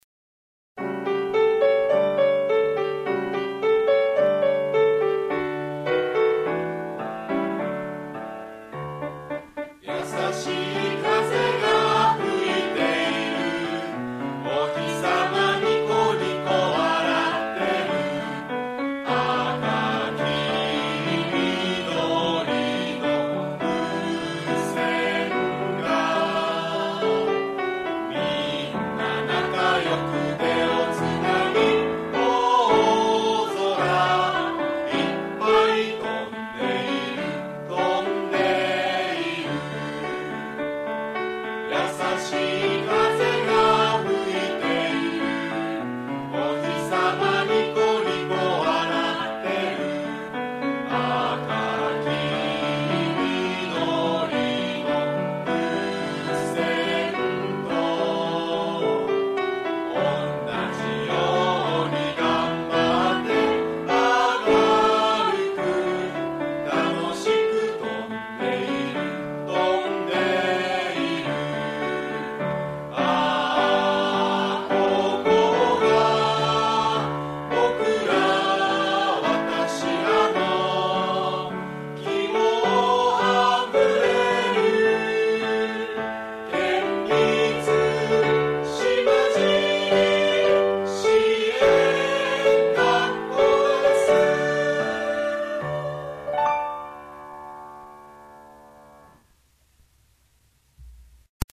島尻特別支援学校 校歌